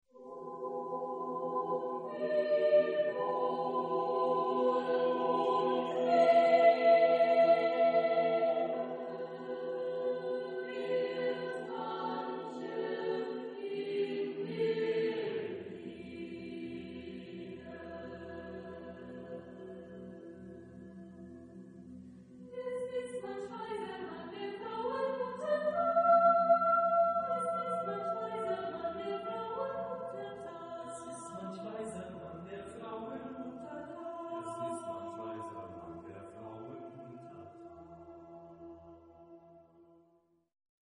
Genre-Stil-Form: Zyklus ; Chorlied ; weltlich
Chorgattung: SSAATBB  (7 gemischter Chor Stimmen )
Tonart(en): e-moll